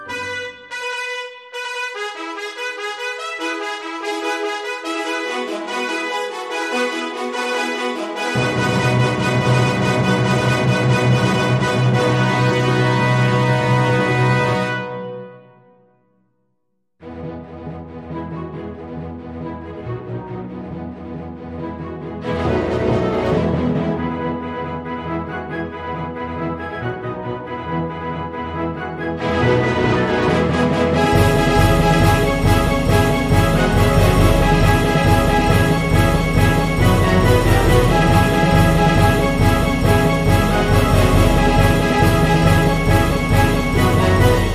クラシック音楽の曲名